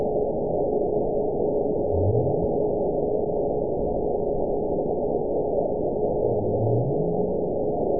event 922242 date 12/28/24 time 22:08:51 GMT (5 months, 3 weeks ago) score 9.59 location TSS-AB04 detected by nrw target species NRW annotations +NRW Spectrogram: Frequency (kHz) vs. Time (s) audio not available .wav